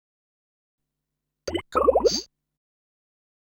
Techno / Voice / VOICEFX165_TEKNO_140_X_SC2.wav